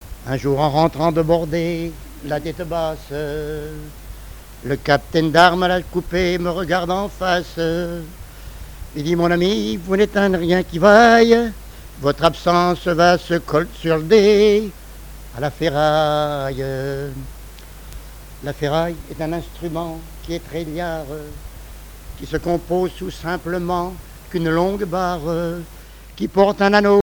chansons
Pièce musicale inédite